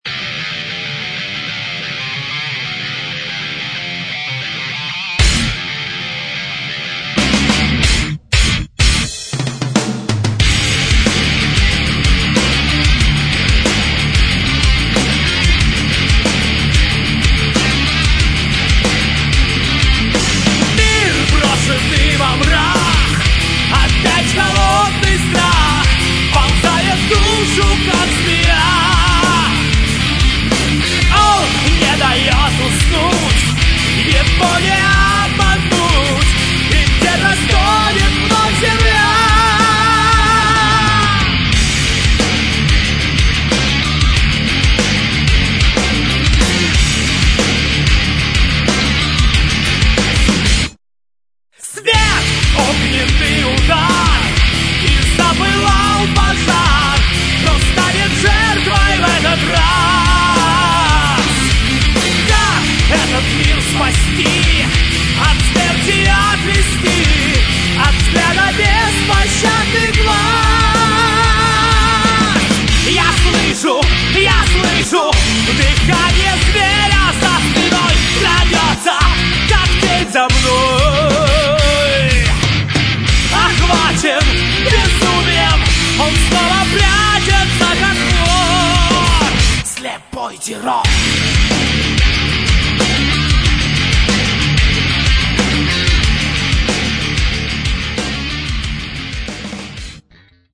Metal
гитары
вокал, клавишные, флейта
бас
ударные